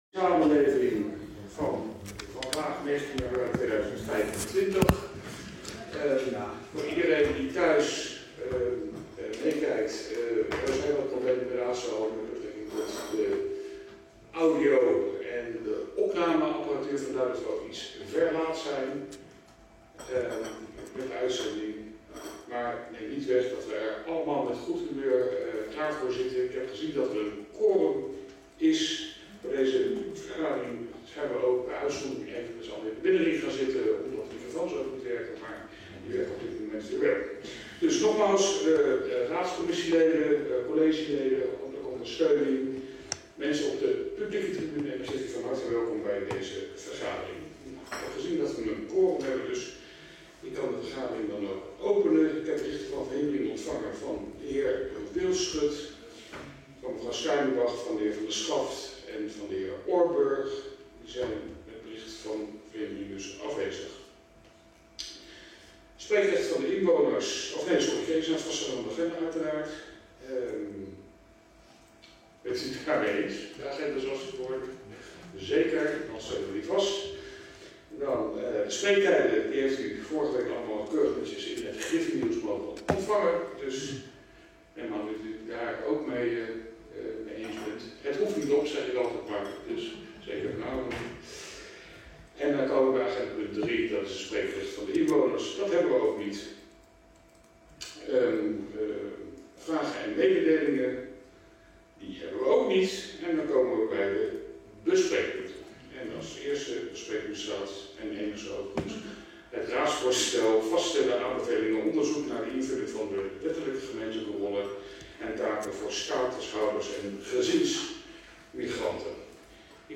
Huize Swaensteyn, Herenstraat 72-74 Voorburg, raadszaal
Als gevolg van een forse technische storing zijn van deze commissievergadering geen videotulen beschikbaar; excuus.
Wel is een - kwalitatief minder goede - opname van alleen het geluid beschikbaar; die hebben we als bijlage geplaatst, zie hieronder.